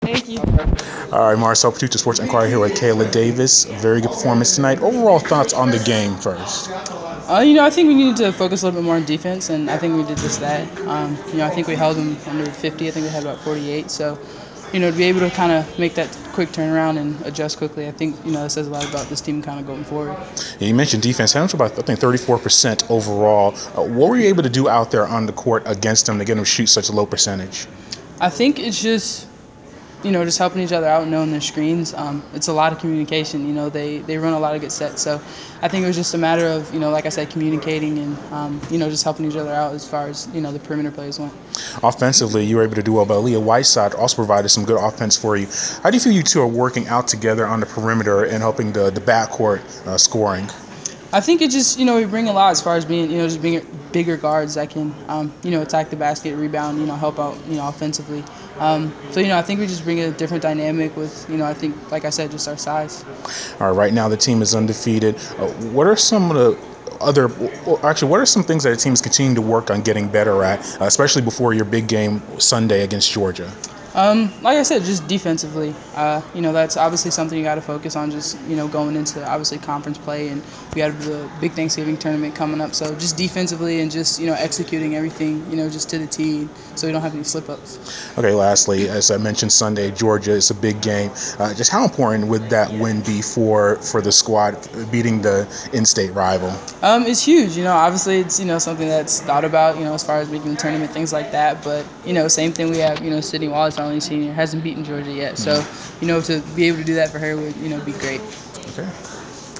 Inside the Inquirer: Interview